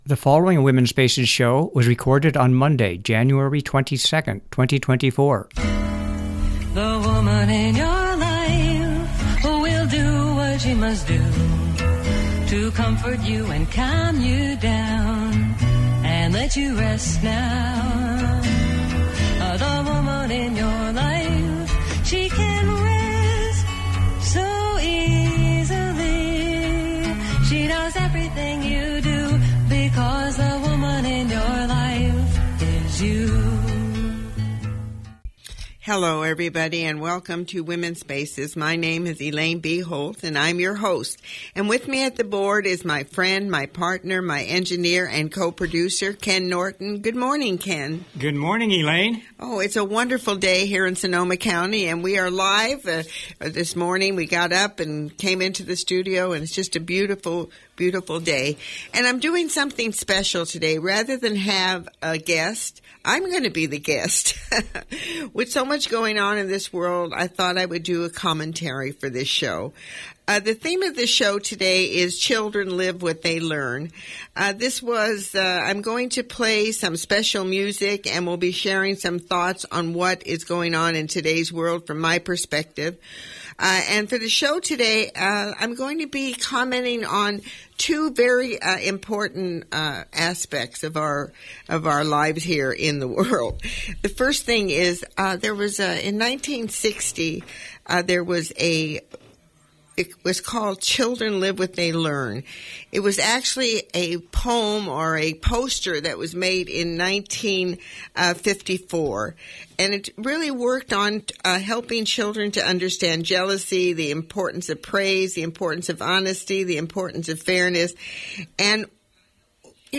I am going to play some special music and will be sharing some thoughts on what is going on in today’s world from my perspective. I am also going to comment on The Seven Principles of Kwaanz a. ---- Children Live What They Learn .